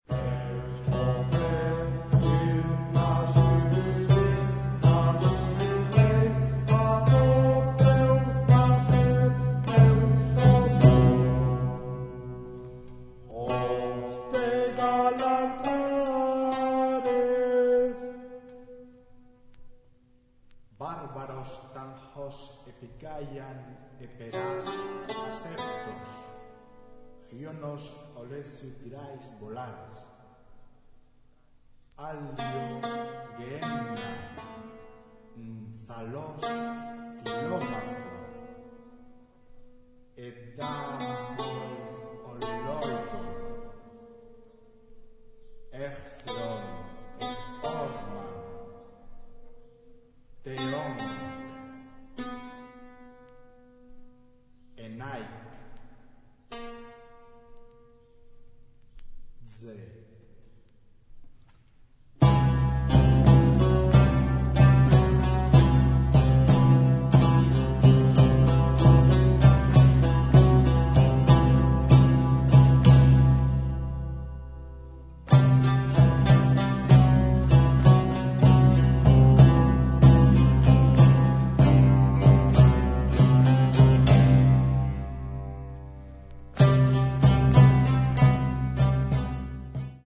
細部に至るまでをも表現するべく、当時の楽器36種類を復元・制作。